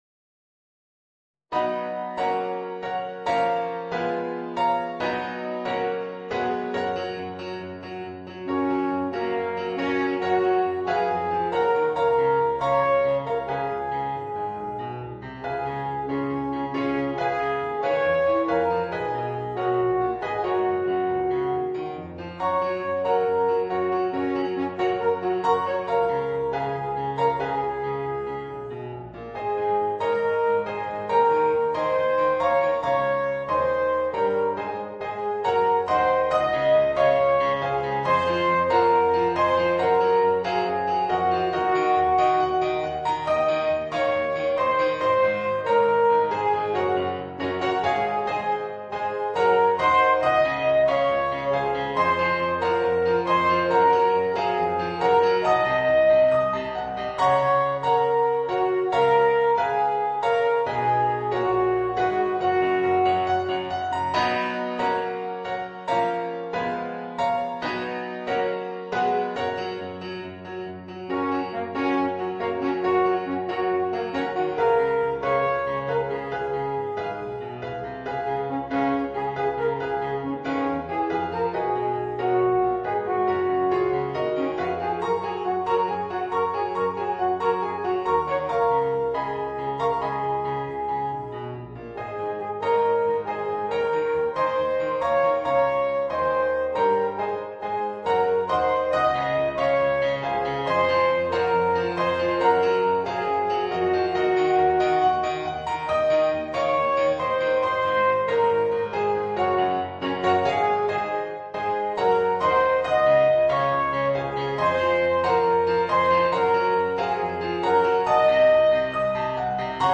Voicing: Alphorn w/ Audio